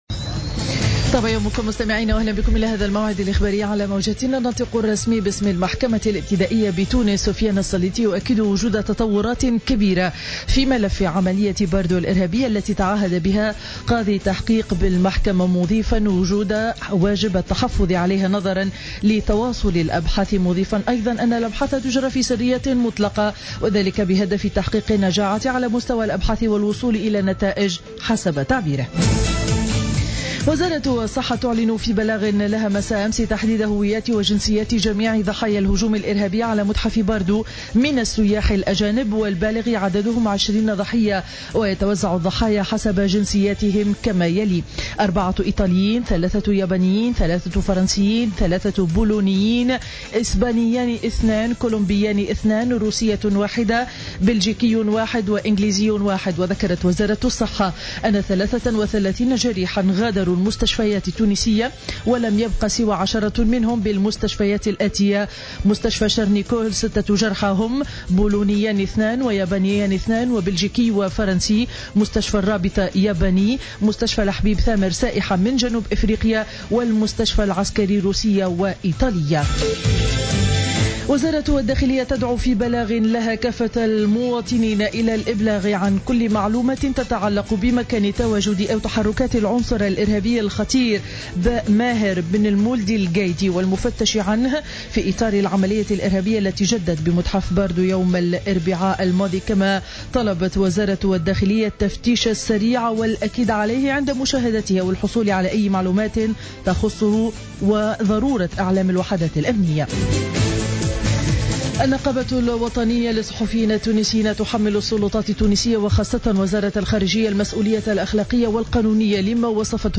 نشرة أخبار السابعة صباحا ليوم الأحد 22 مارس 2015